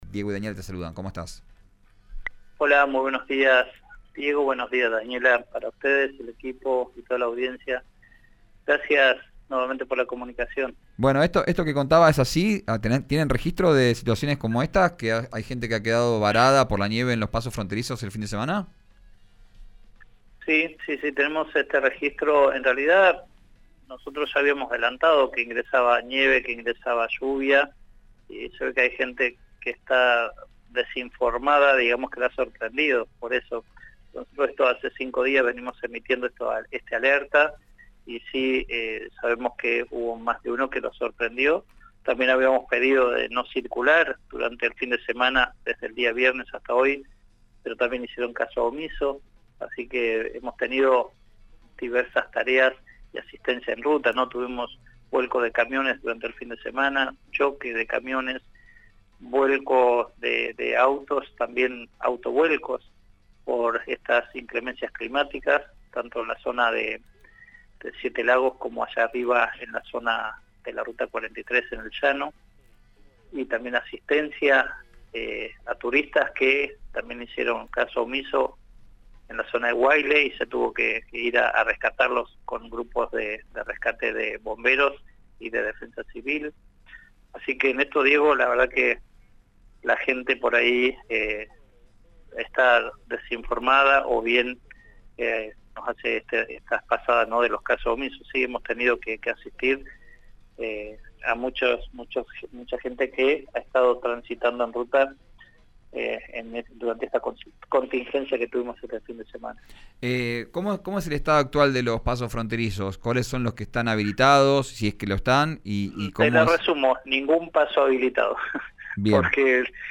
Giusti informó esta mañana, a primera hora, en RÍO NEGRO RADIO que «los cinco pasos fronterizos de Neuquén estaban cerrados por presencia de nieve desde Villa la Angostura hasta Caviahue y Copahue».
Escuchá a Martín Giusti, en RÍO NEGRO RADIO: